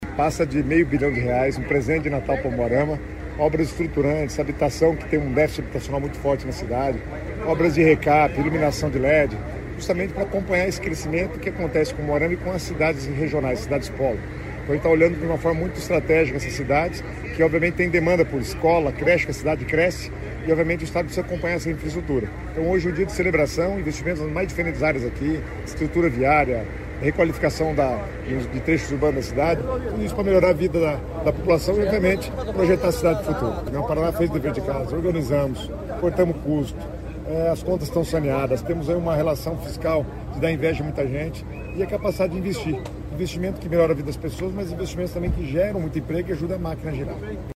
Sonora do secretário Estadual das Cidades, Guto Silva, sobre os investimentos de R$ 90 milhões em Umuarama